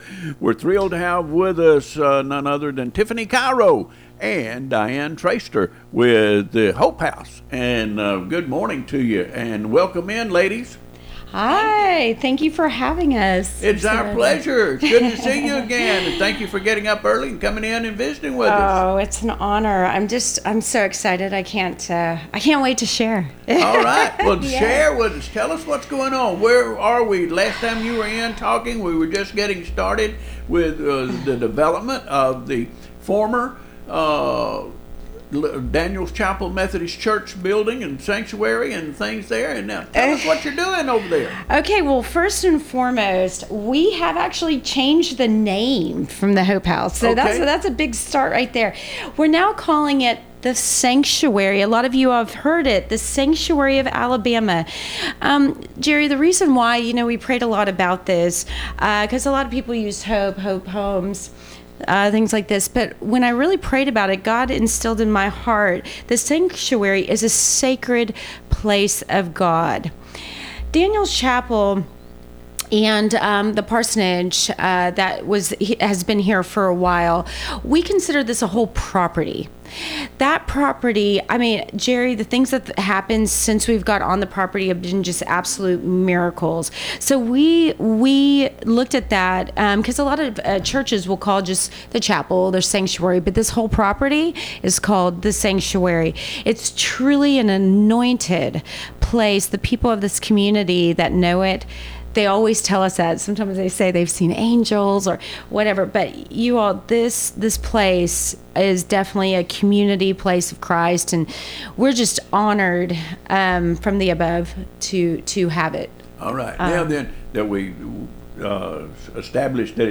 The Sanctuary welcomes women regardless of their background or past, offering them time, support, and community without a set limit on how long they can stay.